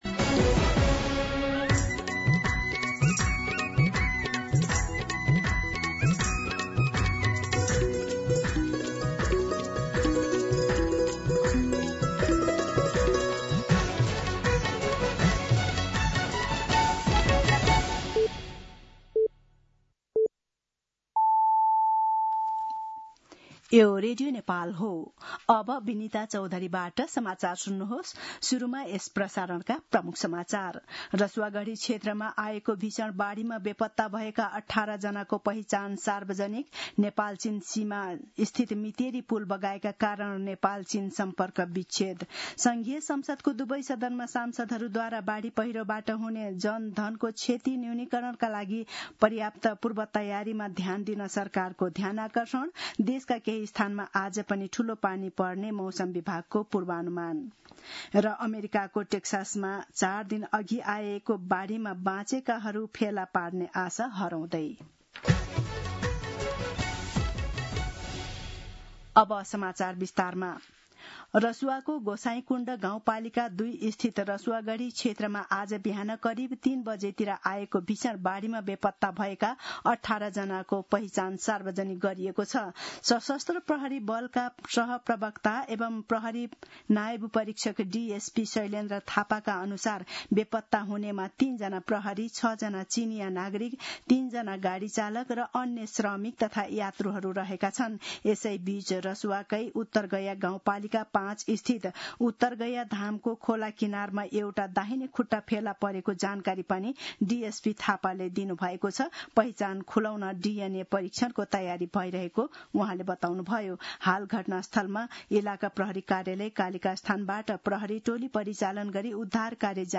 दिउँसो ३ बजेको नेपाली समाचार : २४ असार , २०८२